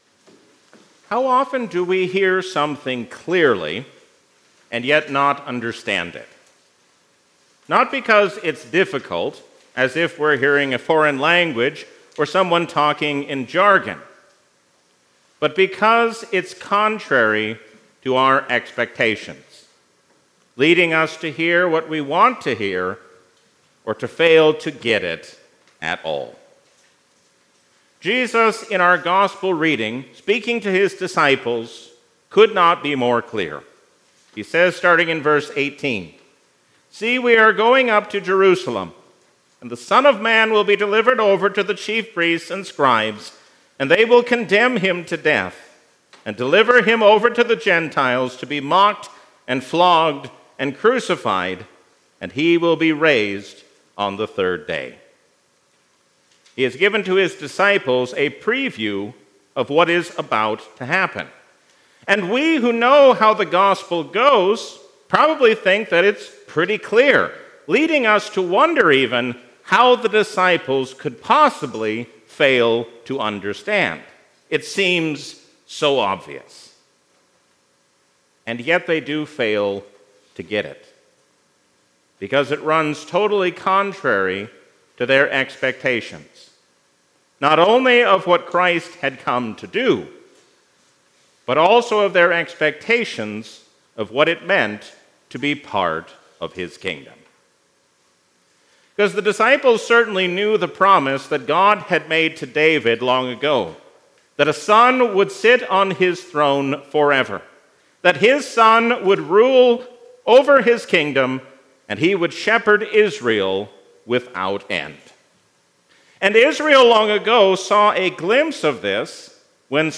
Sermons – St. Peter and Zion Lutheran